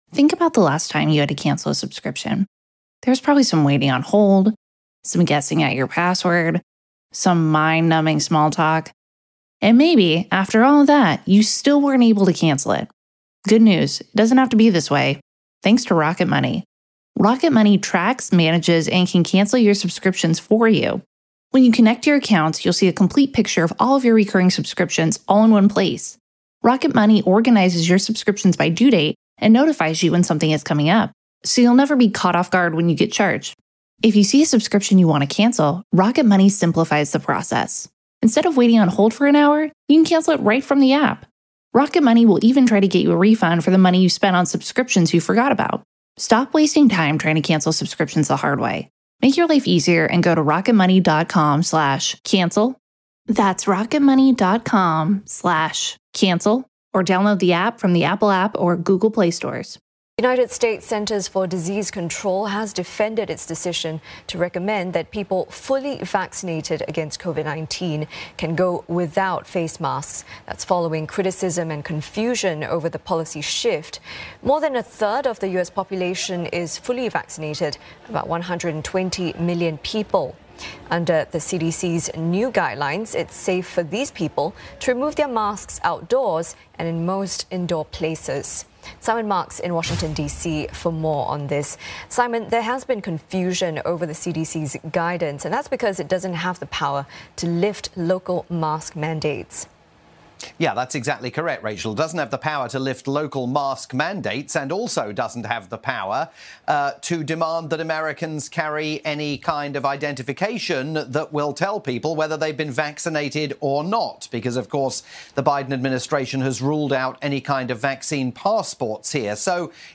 live report for CNA